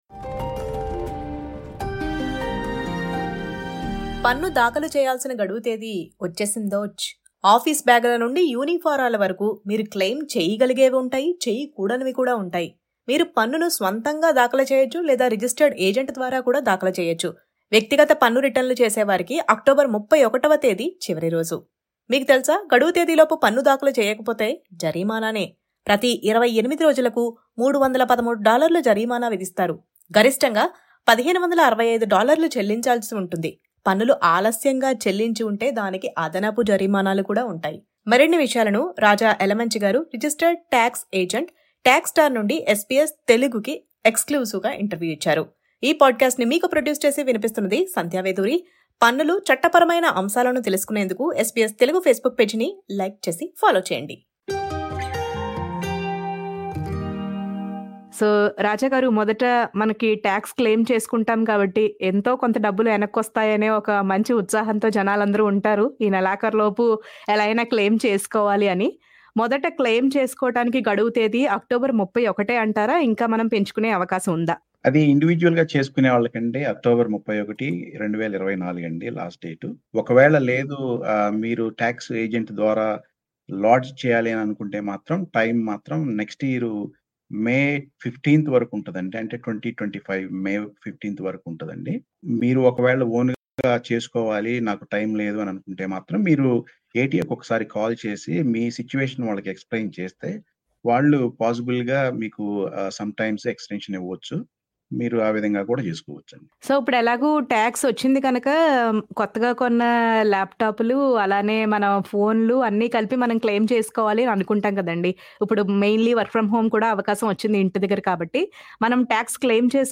మరిన్ని వివరాలను SBS తెలుగు ప్రత్యేక ఇంటర్వ్యూలో